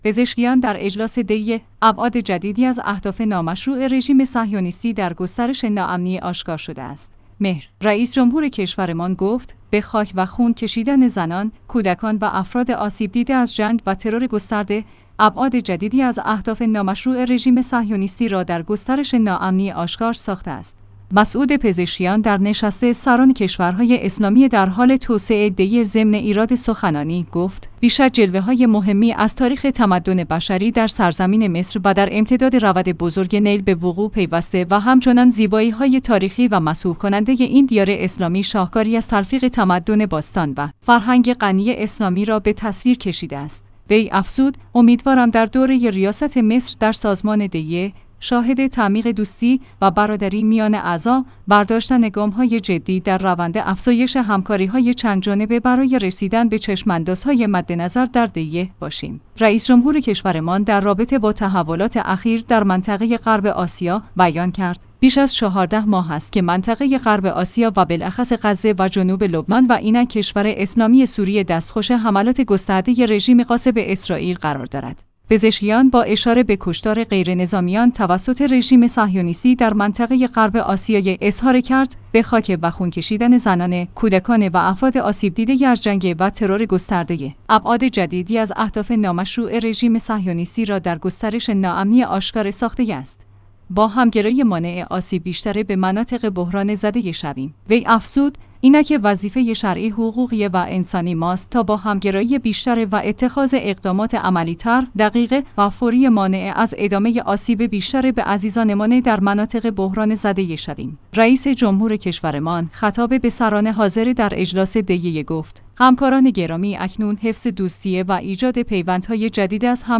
مسعود پزشکیان در نشست سران کشورهای اسلامی در حال توسعه (دی ۸) ضمن ایراد سخنانی، گفت: بی‌شک جلوه‌های مهمی از تاریخ تمدن